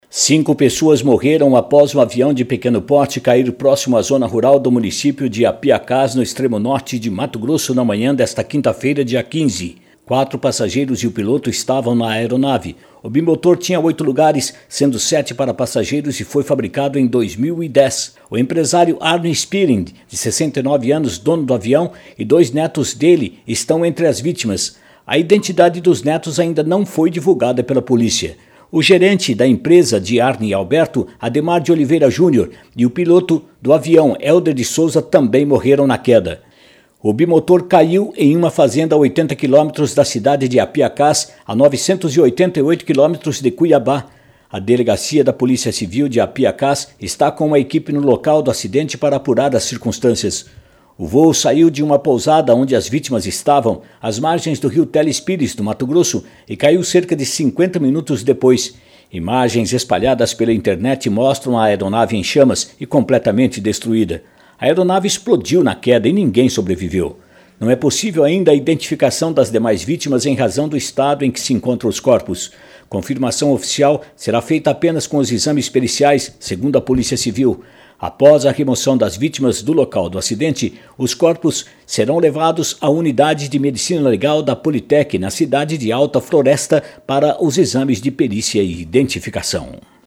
Aviao-de-pequeno-porte.mp3